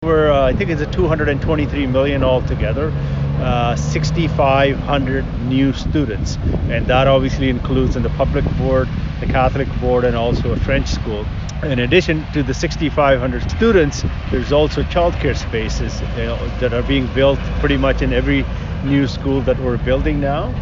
Milton MPP Parm Gill held a press conference at the building site along James Snow Parkway, just south of Louis St. Laurent Avenue on Monday morning.